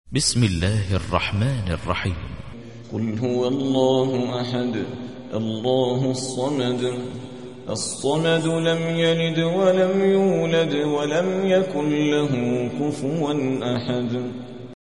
تحميل : 112. سورة الإخلاص / القارئ عادل ريان / القرآن الكريم / موقع يا حسين